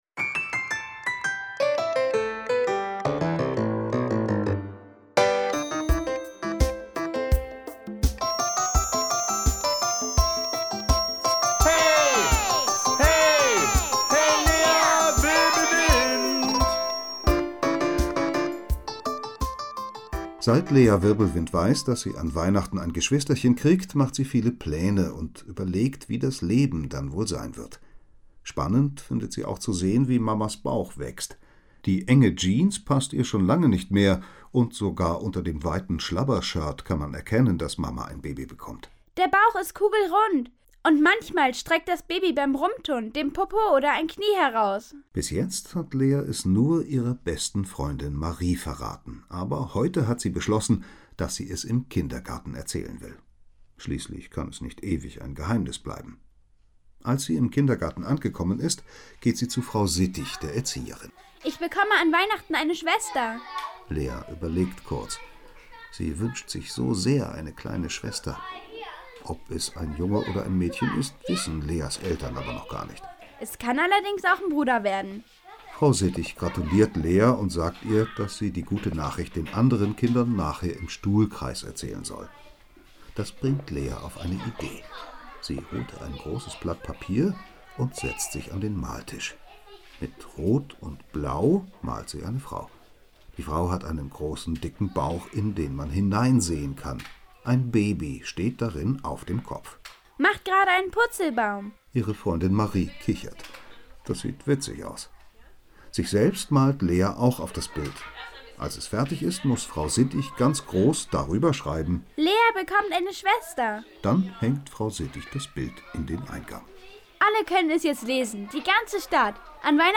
Hörbuch, 1 CD, 42 Minuten